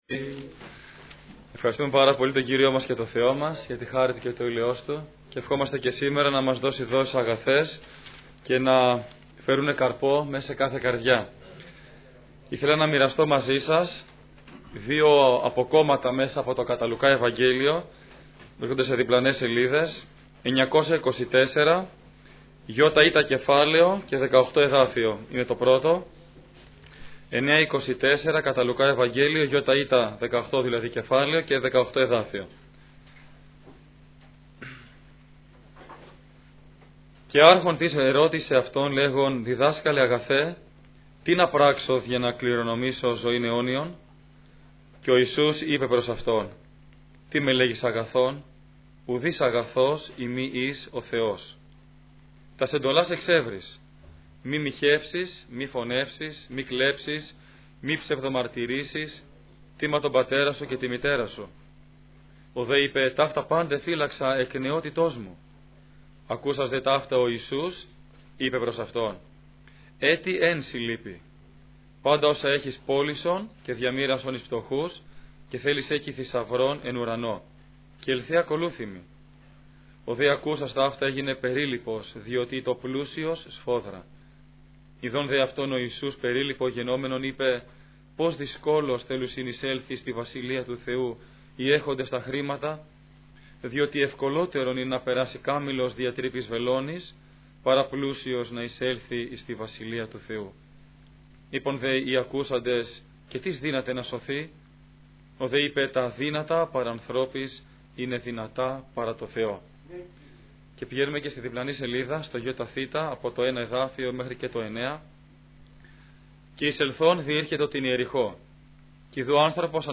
Κηρύγματα Ημερομηνία